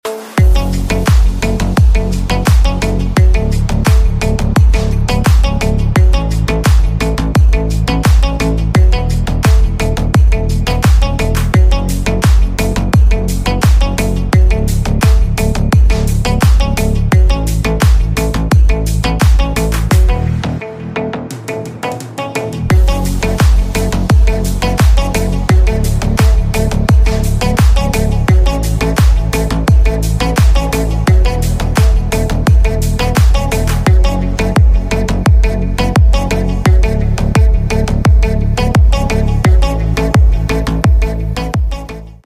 Three Layers PPR Plastic Pipe sound effects free download
Three Layers PPR Plastic Pipe Extrusion Line 20-75mm, HDPE PPR Pipe Extruder Machine. The ppr pipe making machine adopts the specialized high efficient wear-resistant extruder for ppr raw materials, which ensures high speed extrusion and guarantee the long service life of extruder.